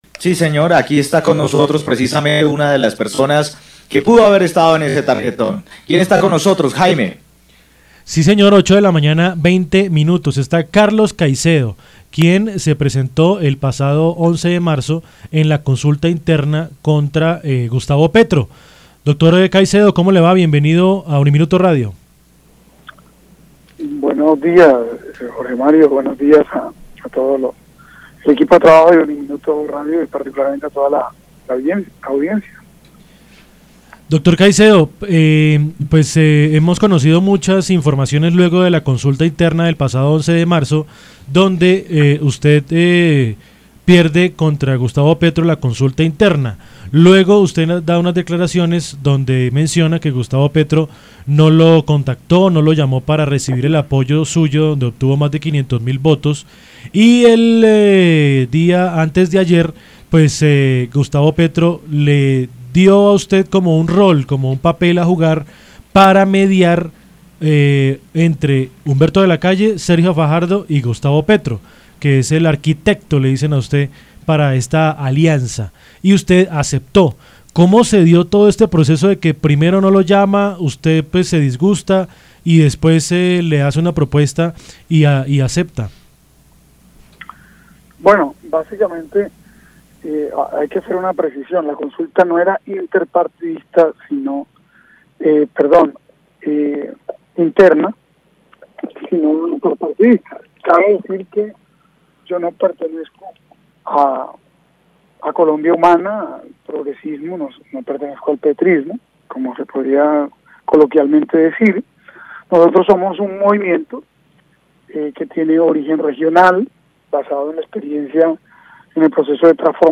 En diálogo con UNIMINUTO Radio estuvo Carlos Caicedo ex candidato presidencial y quien aceptó ser el “arquitecto” para lograr una posible alianza entre Gustavo Petro, Sergio Fajardo y Humberto De La Calle.
Entrevista-a-Carlos-Caicedo-ex-candidato-alianza-con-Petro.mp3